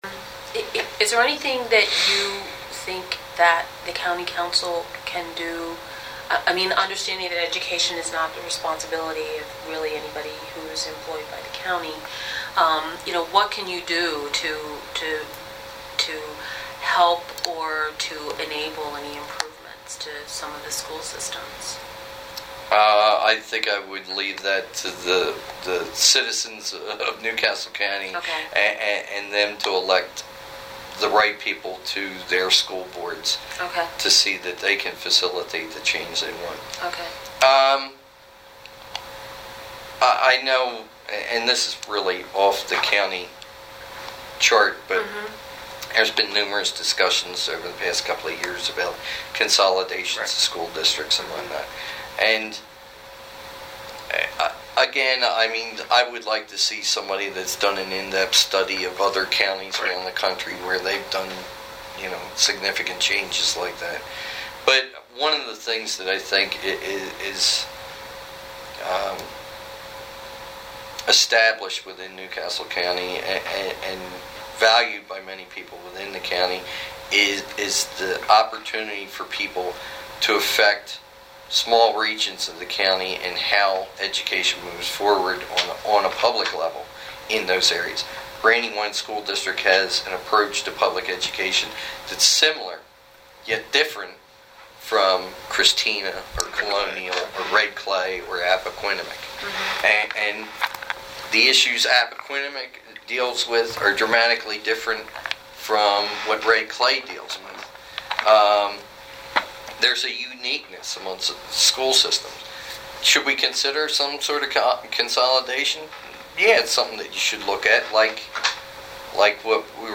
Here is part 6 of our interview, focusing on reducing the size of County Council and whether consolidation of School Districts is a good idea.